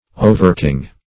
Search Result for " overking" : The Collaborative International Dictionary of English v.0.48: Overking \O"ver*king`\, n. A king who has sovereignty over inferior kings or ruling princes.